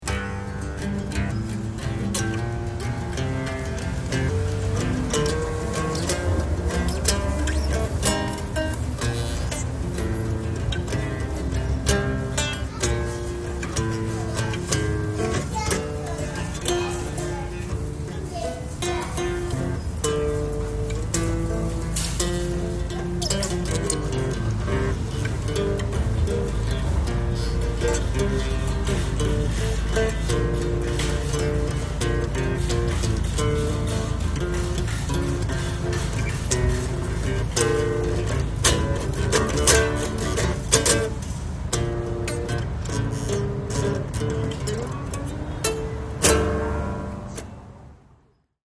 improvised tune